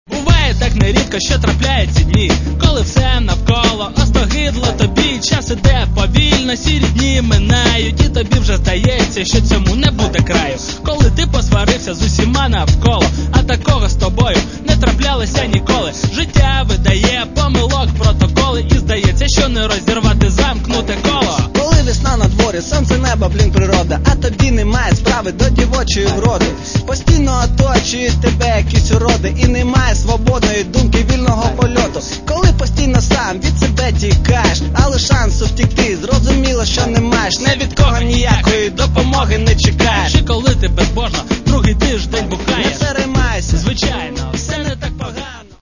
Каталог -> Хіп-хоп